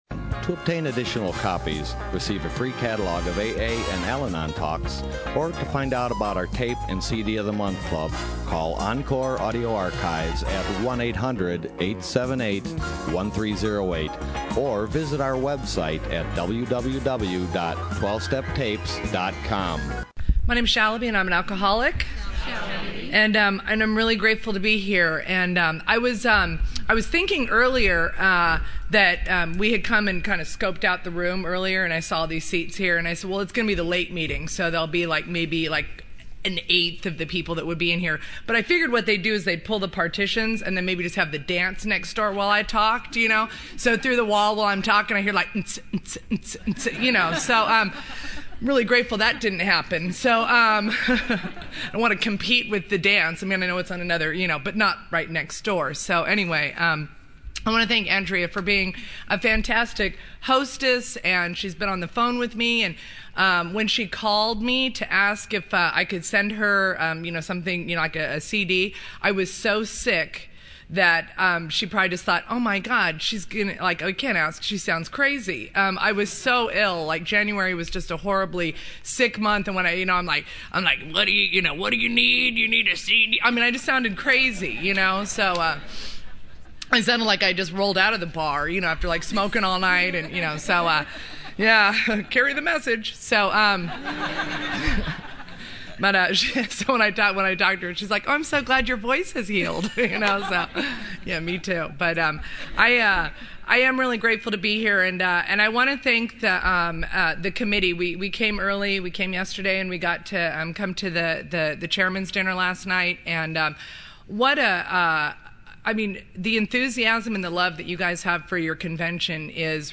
Orange County AA Convention 2013